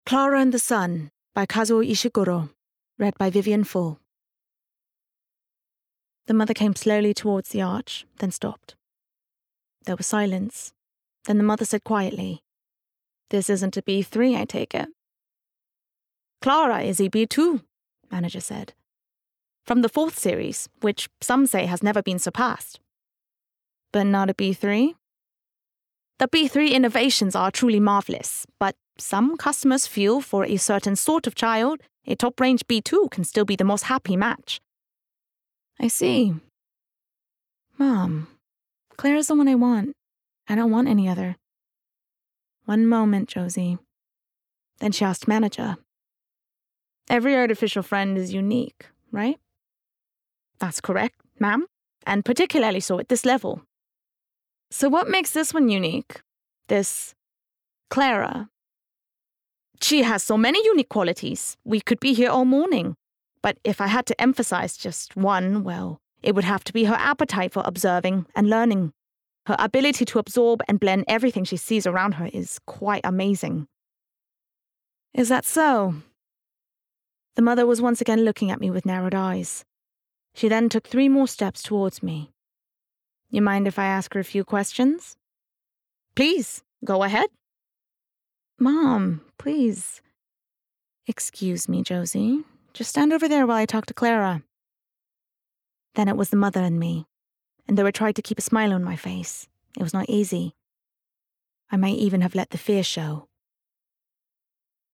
Versatile/Contemporary/Youthful
• Audio Books